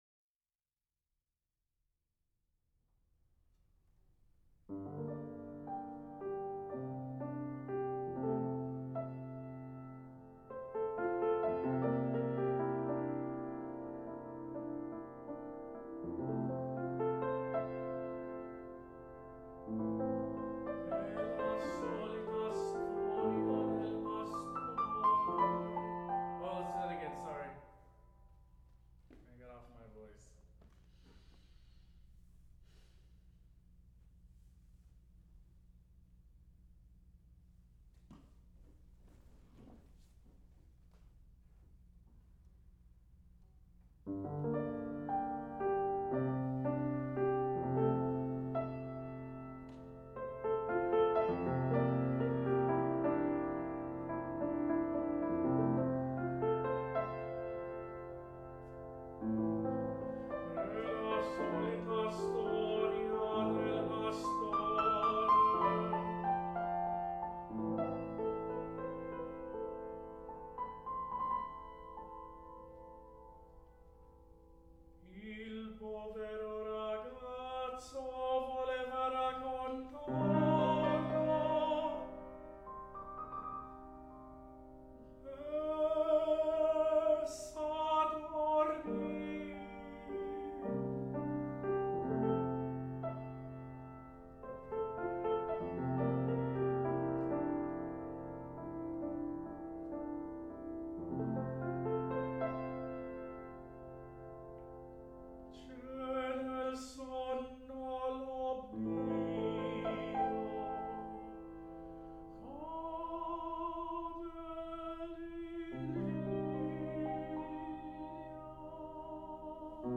Saint Lucian born lyric tenor has died.
Los Angels recordings